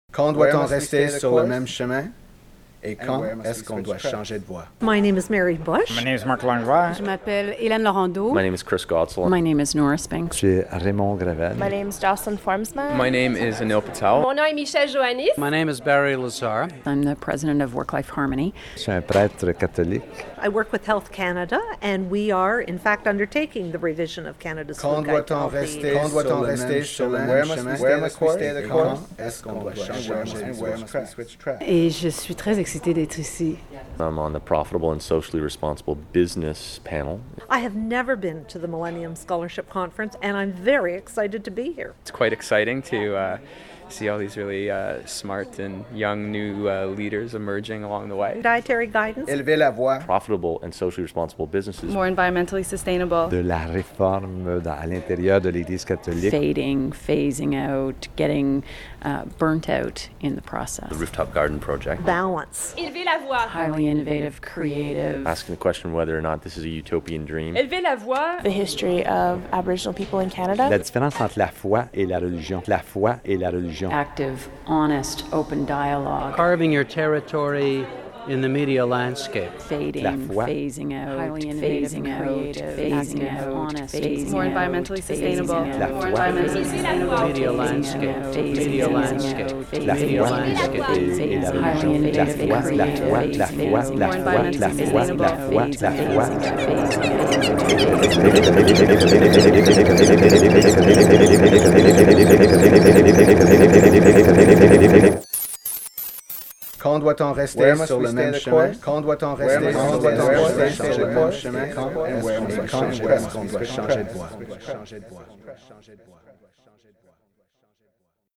Think Again 2005 “Switch Tracks” audio montage, 2006, 1:58